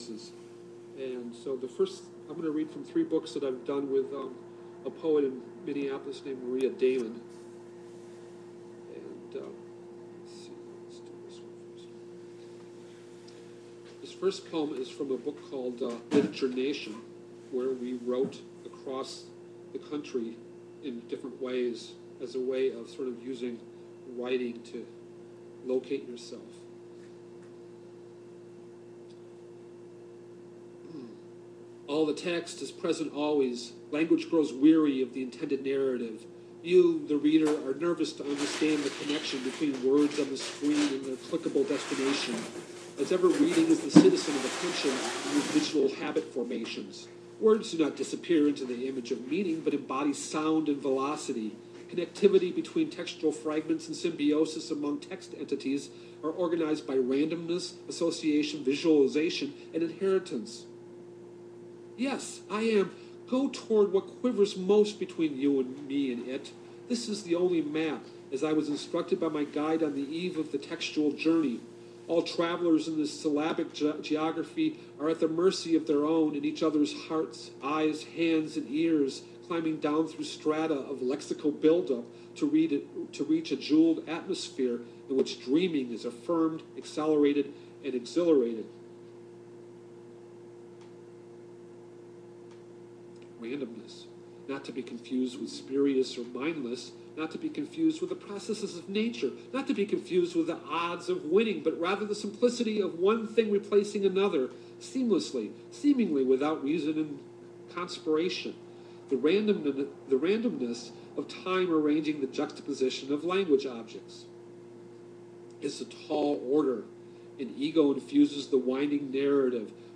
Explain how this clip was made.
on WDRT radio in Wisconsin